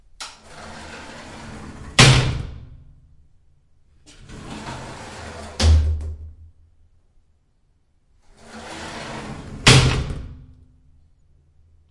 随机的 " 淋浴门沉重的玻璃滑开关闭砰砰声
描述：淋浴门重型玻璃幻灯片打开关闭thud slam.flac